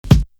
Tardy Kick.wav